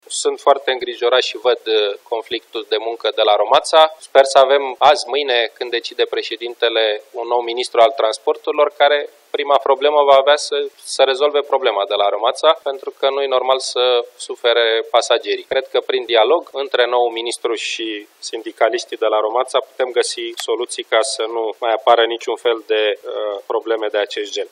La începutul ședinței de Guvern de la Palatul Victoria, premierul i-a cerut, miercuri, președintelui Klaus Iohannis să se hotărască în cazul numirii noului ministru al Transporturilor, un ministru de la care Victor Ponta așteaptă să rezolve tocmai problema controlorilor de trafic.
15iul-13-Ponta-sper-sa-avem-ministru-ROMATSA.mp3